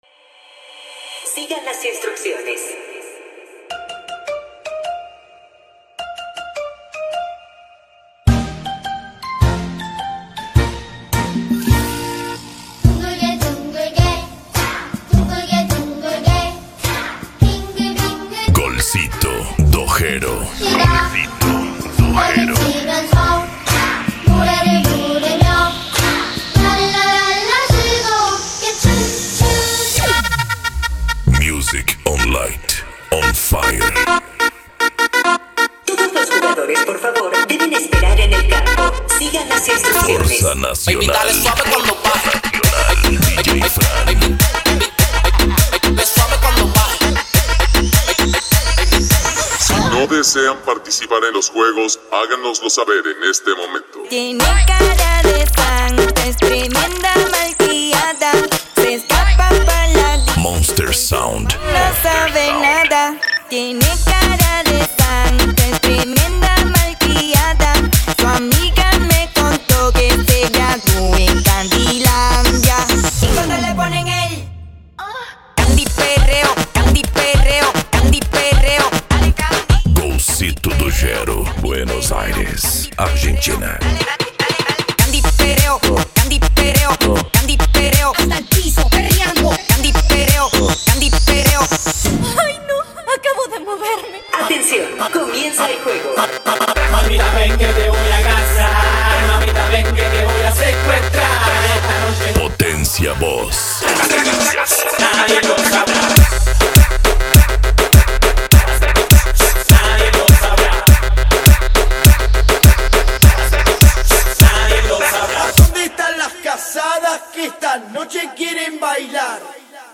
Bass
PANCADÃO
Remix